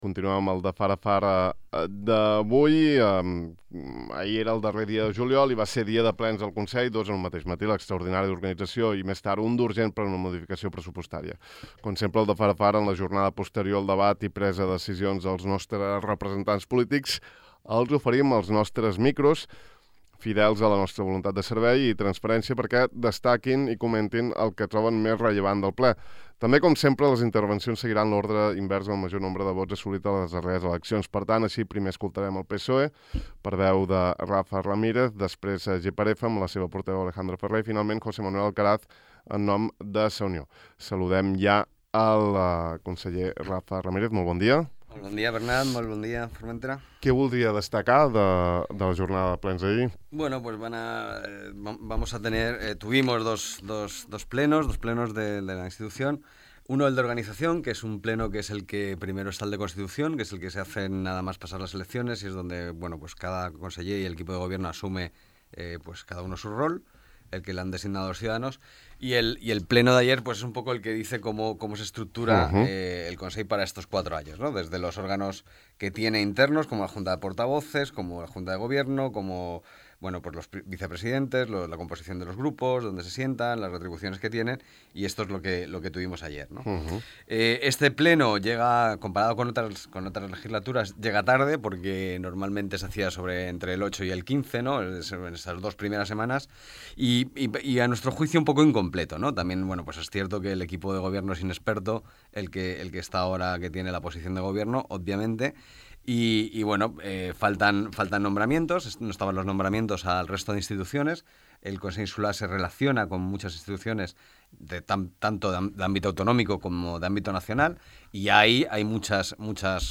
També com sempre, les intervencions han seguit l’ordre invers al nombre més gran de vots assolit a les darreres eleccions, per tant, així, primer hem escoltat al PSOE, per veu de Rafa Ramírez, després GxF , amb la seva portaveu Alejandra Ferrer i finalment a José Manuel Alcaraz, en nom de Sa Unió.